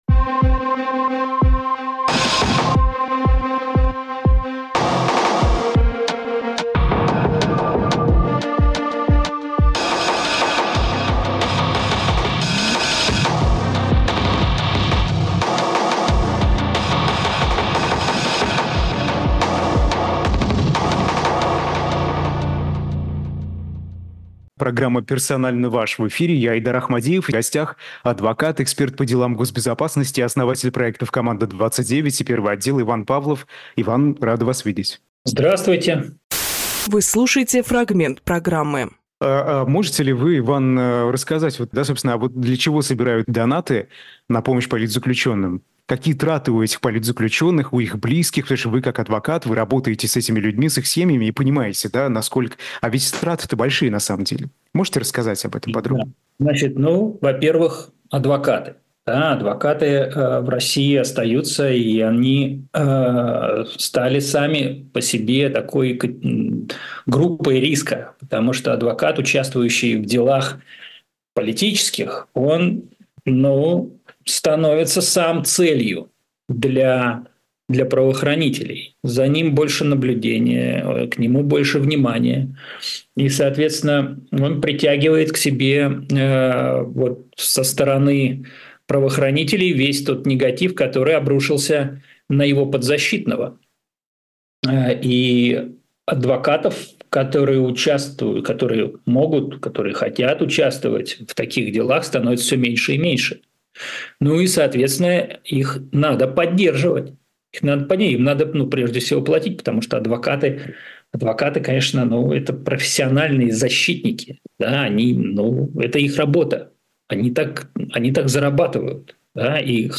Фрагмент эфира от 12 июня.